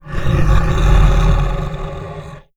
MONSTER_Growl_Medium_12_mono.wav